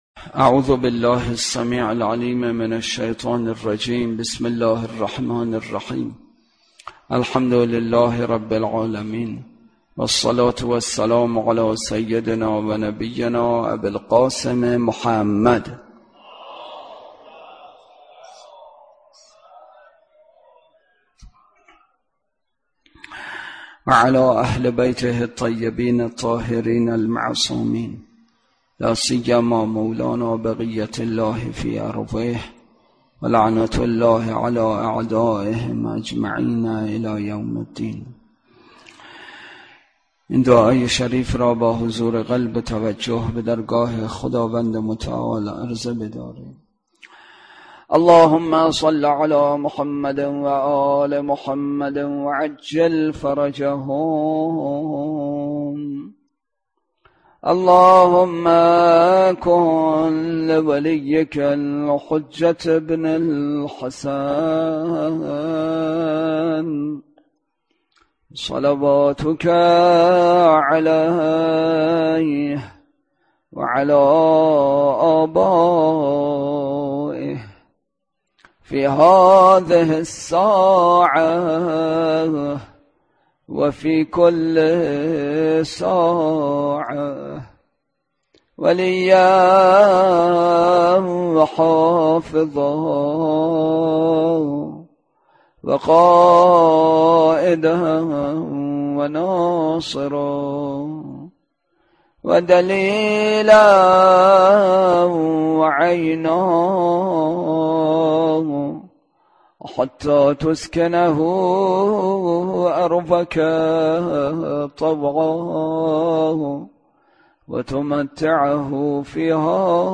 بحث اصلی: شأن نزول سوره‌ی کافرون، شرح و توضیح آیات اول تا پنجم سوره‌ی کافرون (معنای عبادت) روضه: حضرت علی اصغر(علیه‌السلام)
برگزارکننده: مسجد اعظم قلهک